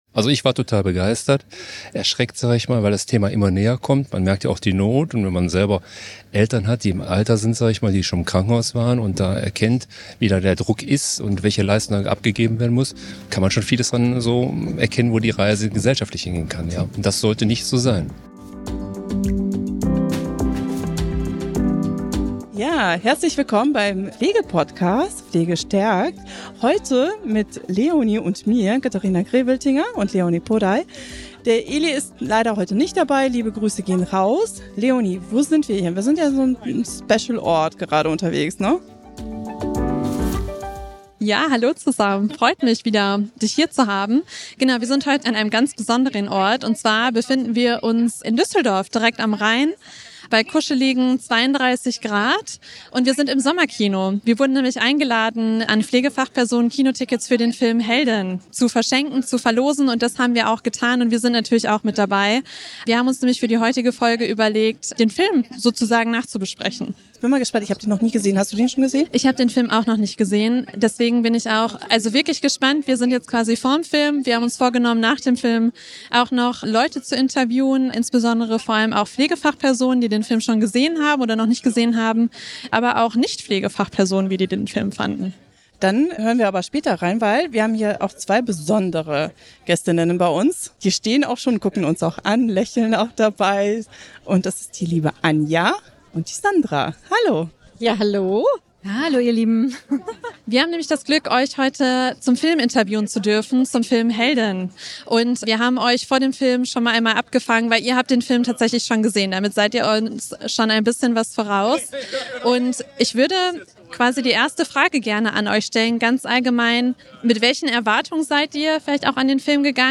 Beschreibung vor 7 Monaten Ein Sommerabend in Düsseldorf, 32 Grad, Open-Air-Kino am Rhein: Der Film „Heldin“ lockt Pflegefachpersonen, Angehörige und Interessierte ins Sommerkino und der Podcast "Pflegestärke" ist mittendrin.